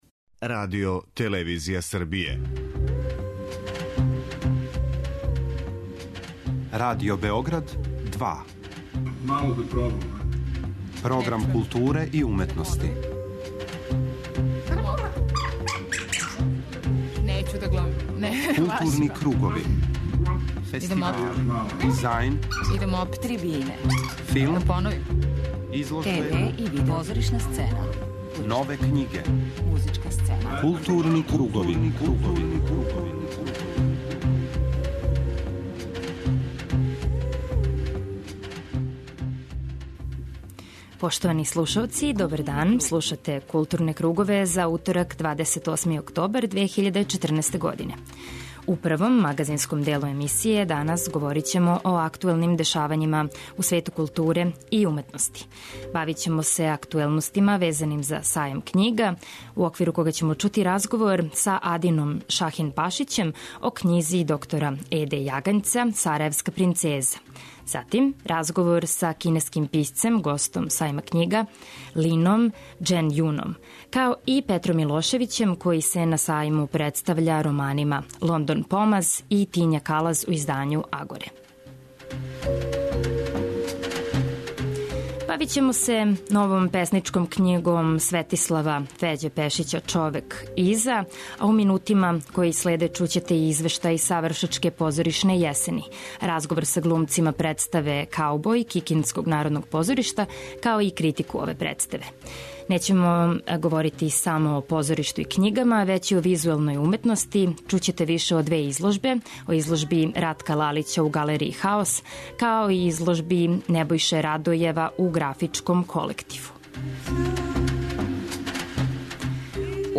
Жирији су своје одлуке саопштили синоћ, а који су награђени филмови чућете у данашњој Филморами, за коју говоре организатори фестивала и награђени аутори.
преузми : 51.38 MB Културни кругови Autor: Група аутора Централна културно-уметничка емисија Радио Београда 2.